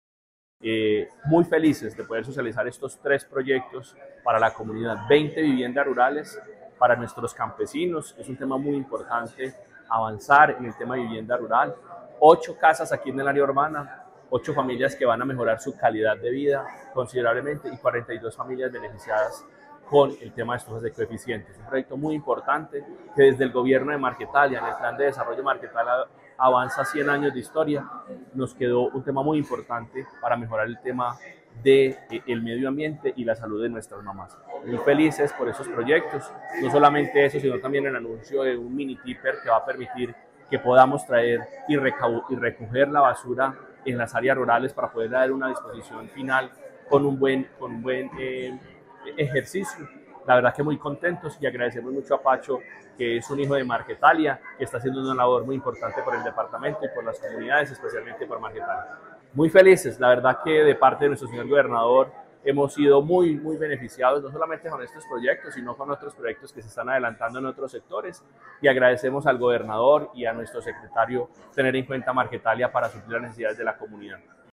Edwin Jesús Sánchez Aristizábal, alcalde de Marquetalia